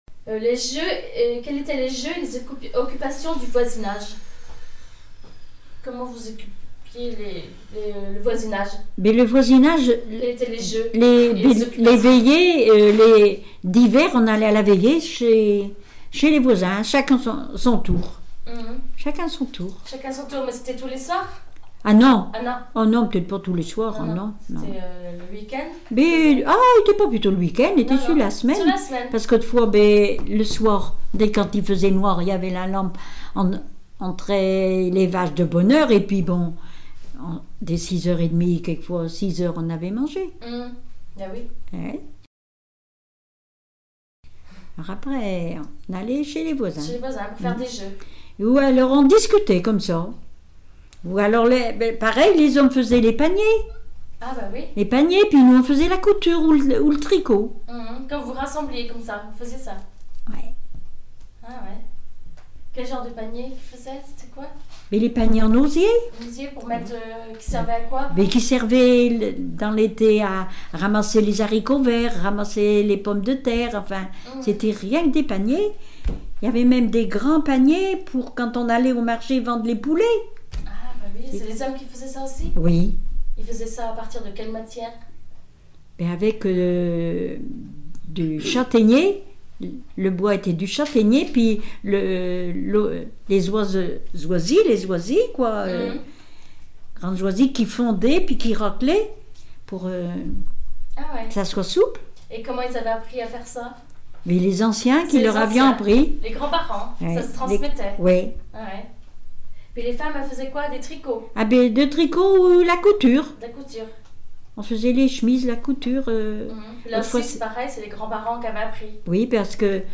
Témoignages sur la vie domestique
Catégorie Témoignage